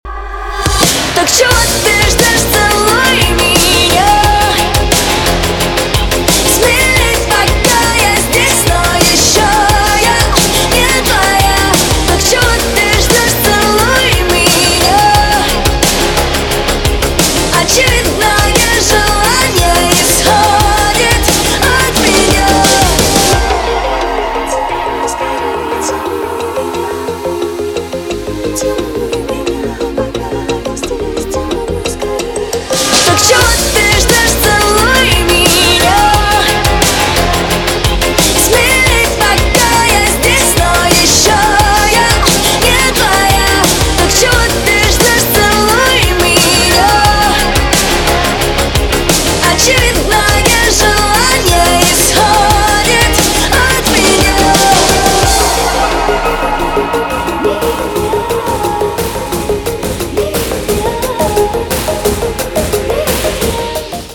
• Качество: 256, Stereo
поп
громкие
женский вокал
Громкая песня от российской поп-исполнительницы.